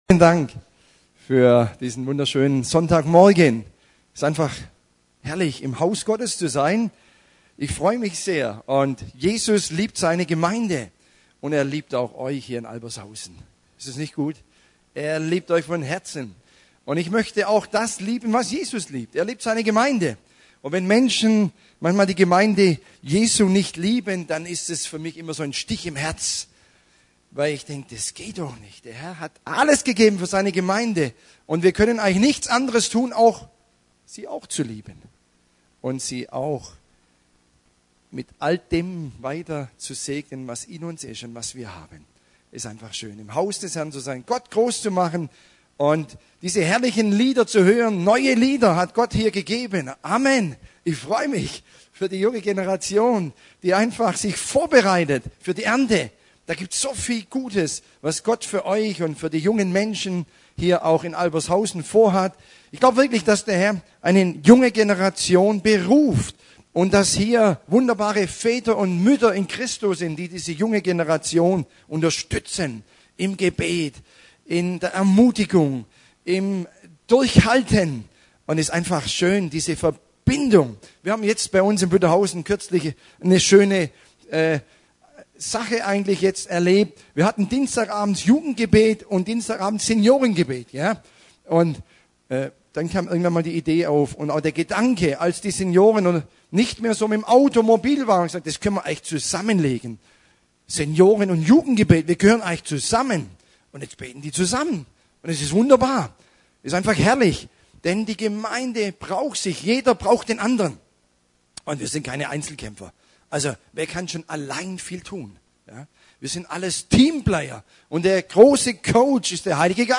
Er diente uns an diesem Sonntag mit dem Wort in dem er schilderte wie wichtig die Charakterschule Gottes für uns ist. Sie kann aber auch nur durch das Wirken des Heiligen Geistes vollkommen gelingen!